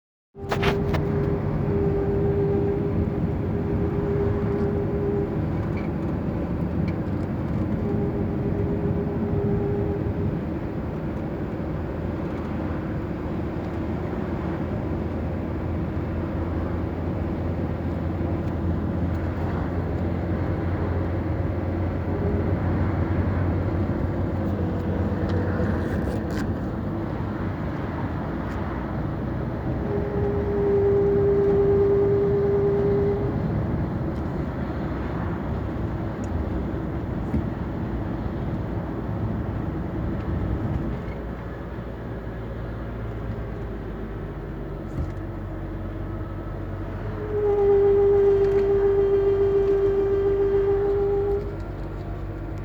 Rumorosità dei freni durante la marcia
Durante le manovre (sia in avanti che in dietro) emette dei fischi striduli fortissimi tanto che la gente si volta anche a distanza...
Anche durante la marcia, in curva (sempre senza toccare i freni) tende a fischiare (tono basso).
allego una registrazione. verso la fine si sente molto bene l'effetto in curva...